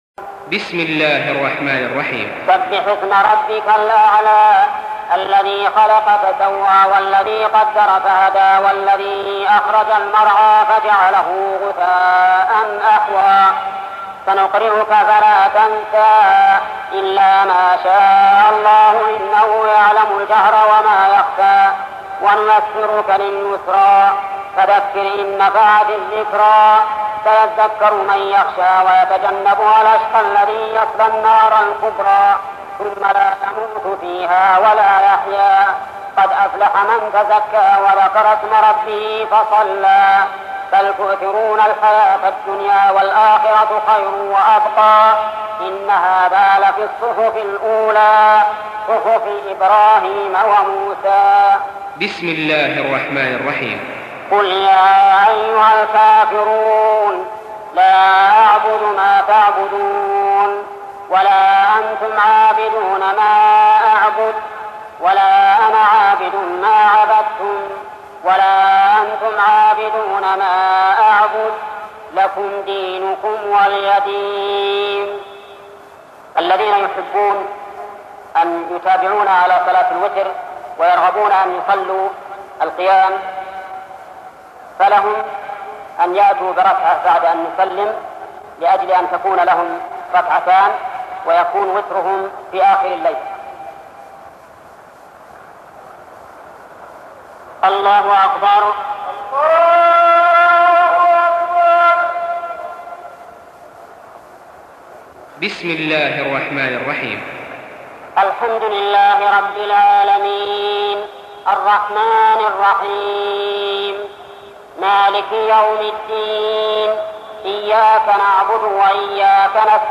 ’Uthaimeen Reciting as Imaam of the Haram in Makkah
’Uthaimeen, may Allaah have mercy on him, as Imaam in the Haram in Makkah in 1982: